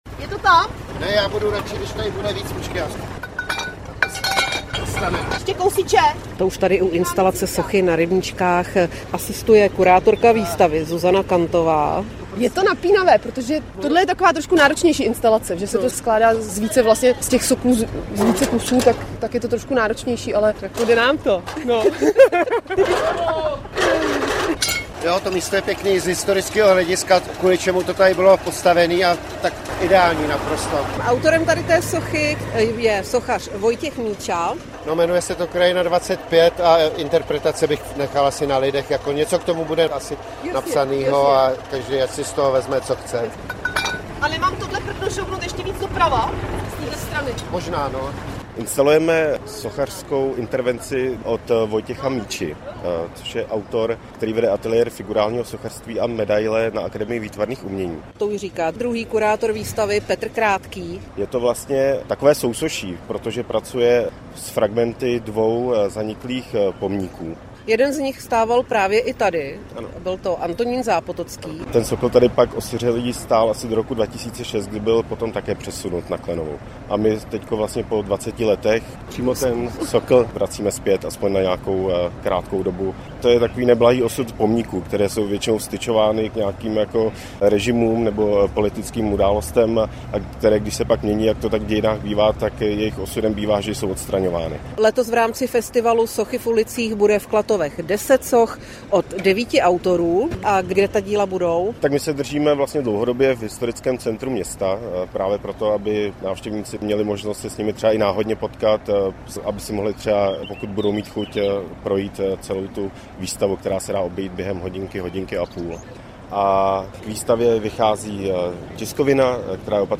Zprávy pro Plzeňský kraj: Fotografie na věži Telecomu i sousoší z fragmentů zaniklých pomníků nabídne festival Sochy v ulicích - 01.05.2025